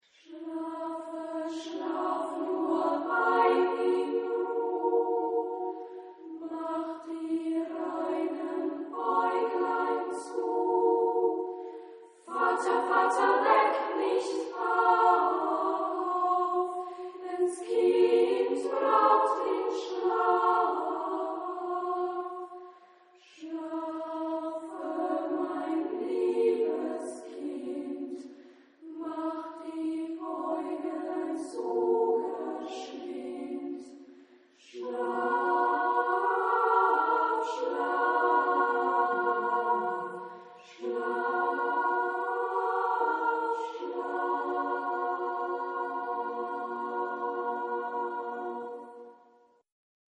Genre-Stil-Form: Volkslied ; Wiegenlied ; Liedsatz ; weltlich
Chorgattung: SAAA  (4 gleichstimmig Stimmen )
Tonart(en): h-moll